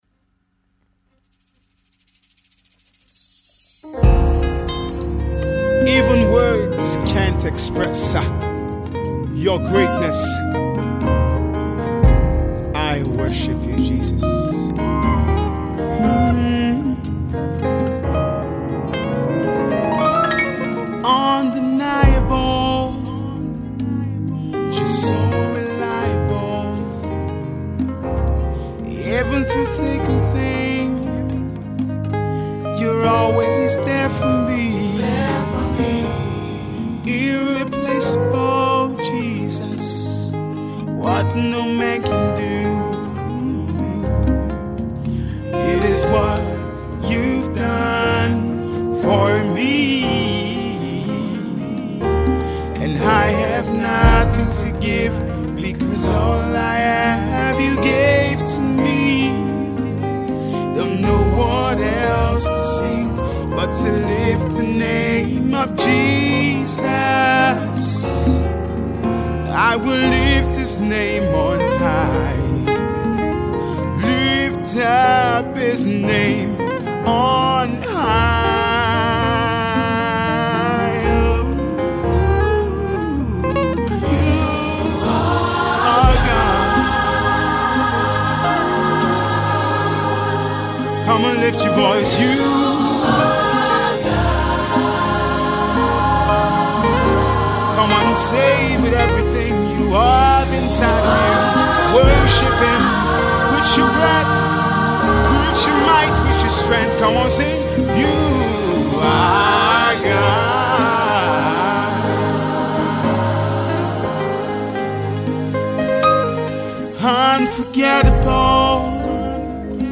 a Nigerian Born gospel artiste based in Canada